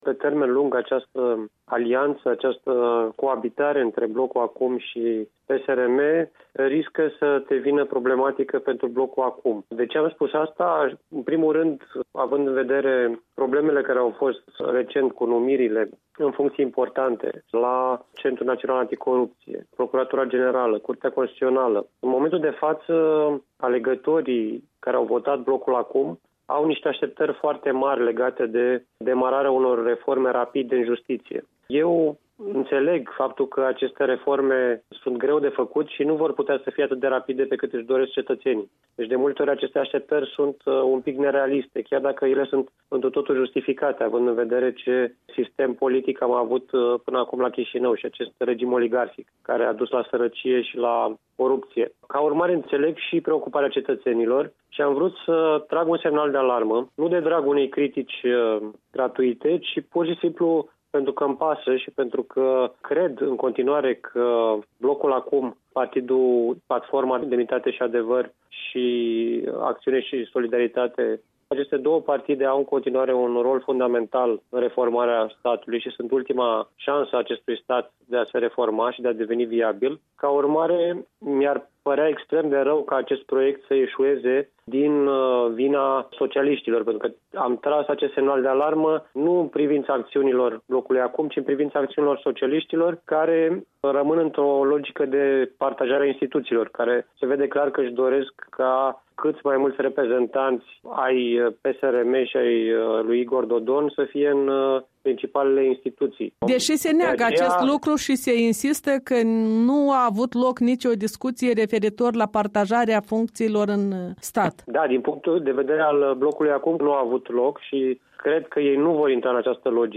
Un interviu cu un deputat român liberal despre ultimele evenimente de la Chișinău.